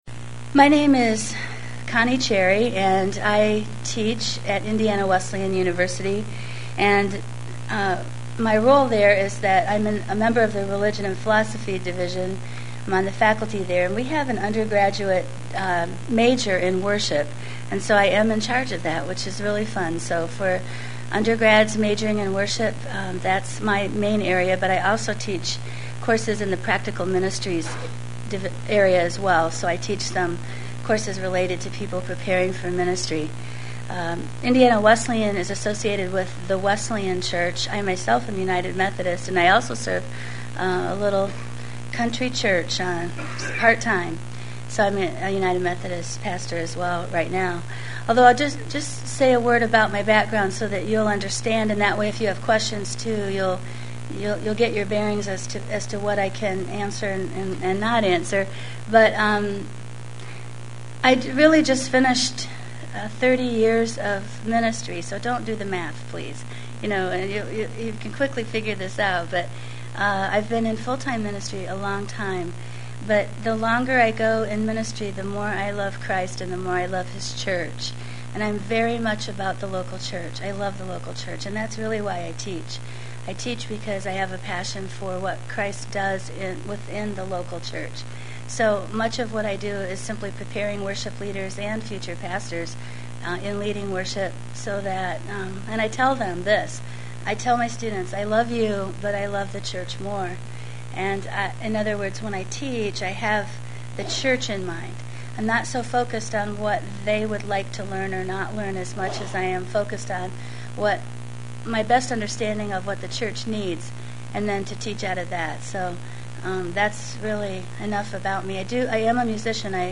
Presented at the 2006 Calvin Symposium on Worship.